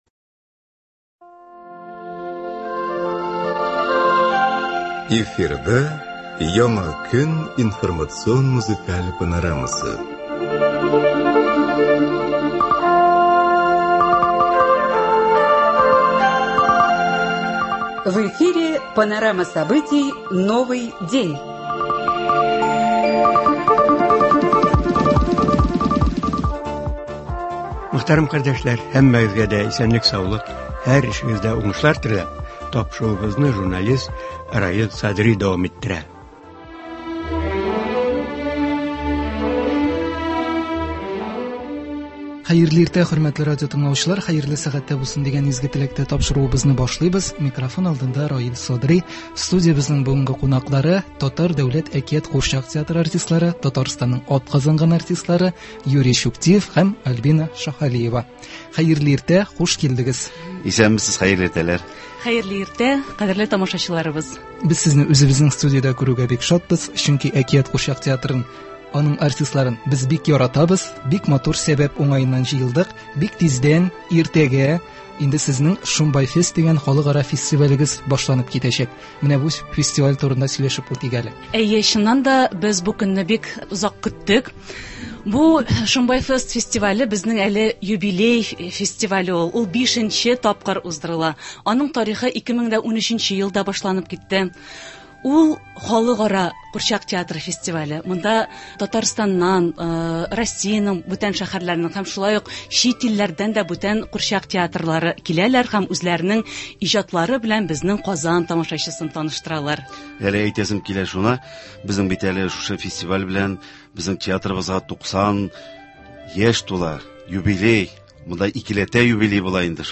Республикабыз театр коллективлары җәйге каникулга әзерләнә. Шул уңайдан тапшыруда “Шомбай фест” Халыкара курчак театрлары фестивале һәм Татар Дәүләт “Әкият” курчак театрының 90 еллыгына багышланган әңгәмә яңгырый.